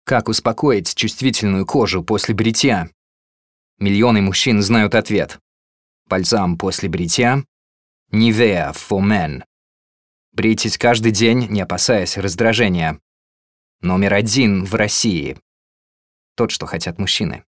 VO russe